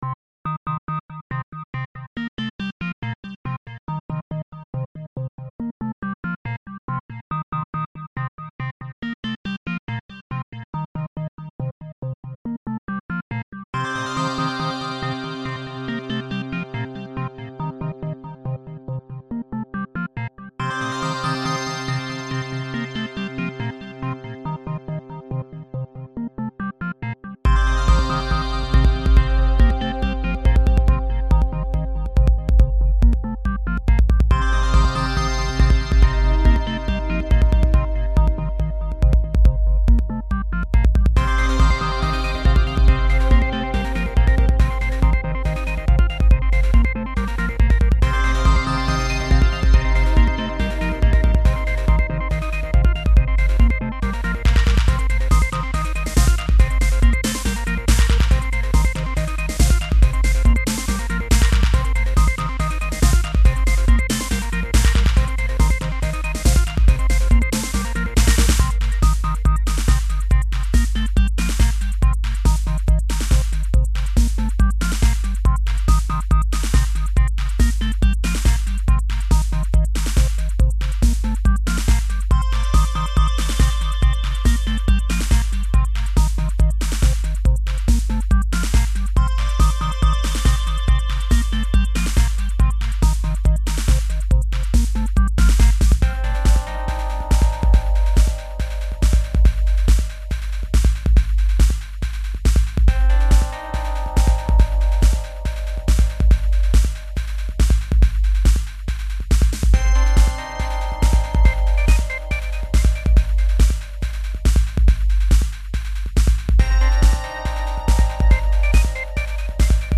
Sounds like a classic platform game theme.
Nostalgic, 8-bit memories!